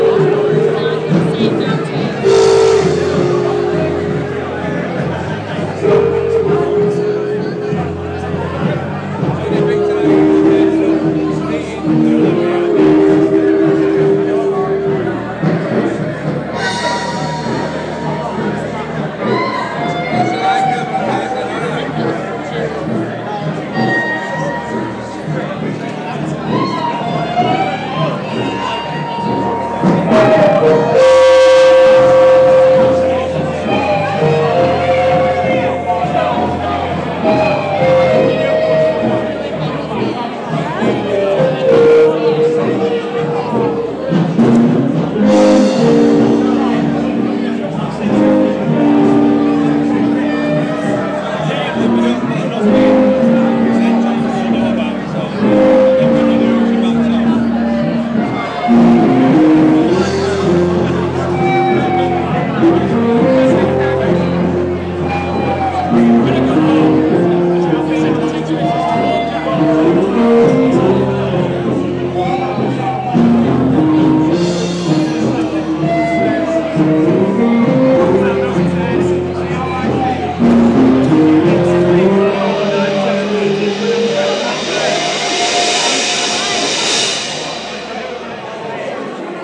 Band in Real Ale pub Leeds. The band was much better than this overloaded recording shows